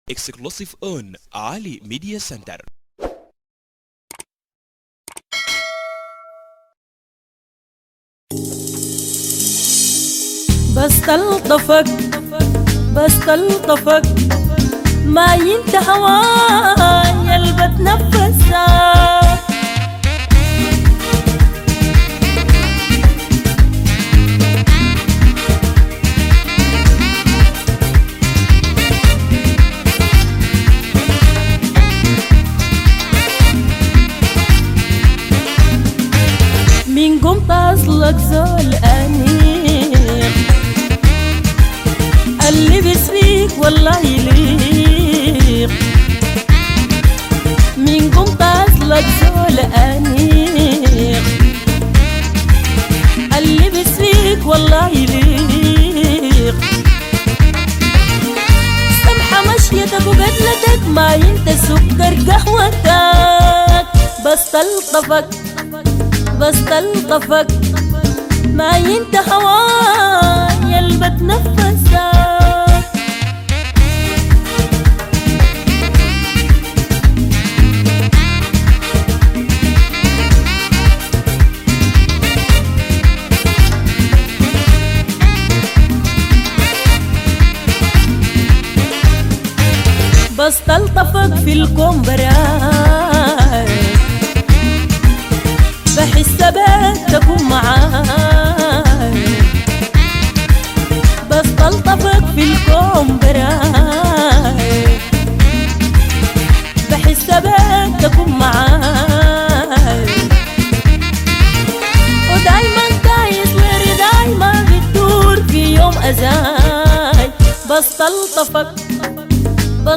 اغاني سودانية